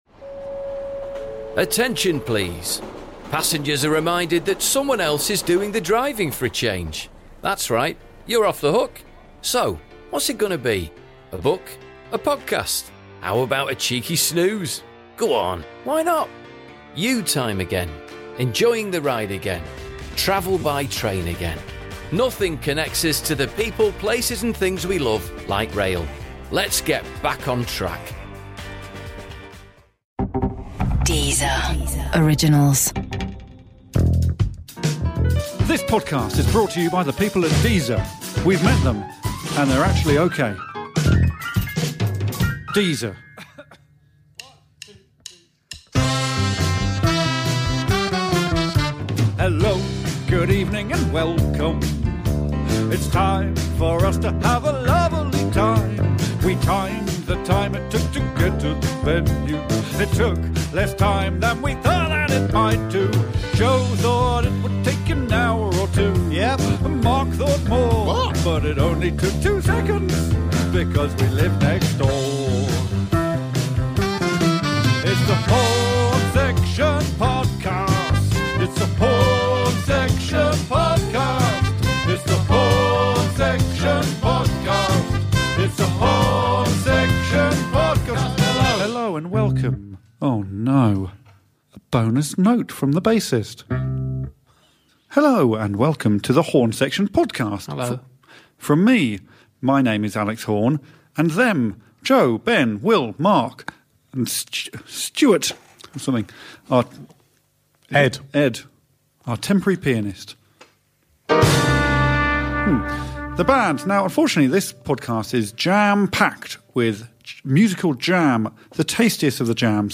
Raucous, ridiculous and surprisingly satisfying - it’s the Horne Section Podcast!
Welcome to The Horne Section Podcast, your new weekly dose of musical nonsense and anarchic chat with Alex Horne and his band! This week we're joined in the studio by television presenter, producer and comedian Richard Osman. Warning: contains some very strong language, and a whole load of lyrical larks.